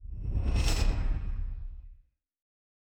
Distant Ship Pass By 7_3.wav